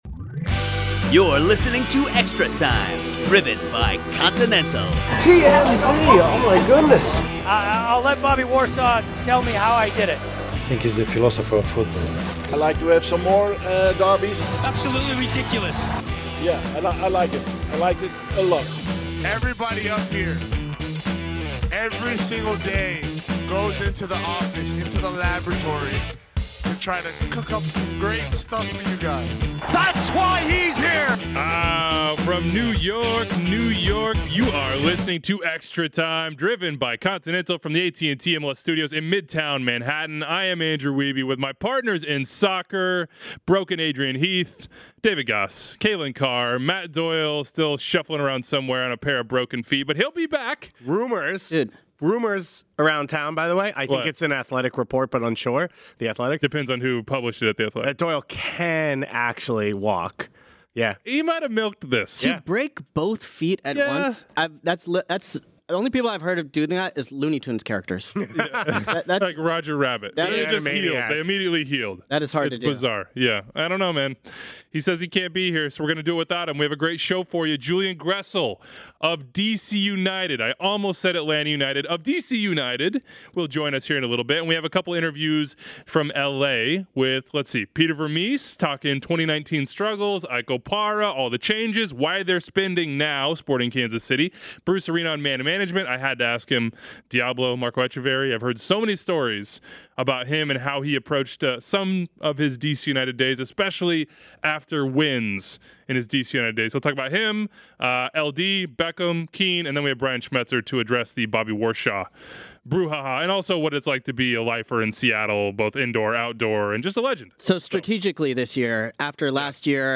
Julian Gressel calls in to explain how the D.C. United trade went down, why he thought he was going to stay in Atlanta until preseason began and what position he’s best suited for (it’s not right back). Three interviews from Los Angeles with MLS legends: Sporting KC’s Peter Vermes on why spending is exploding at his club and around MLS.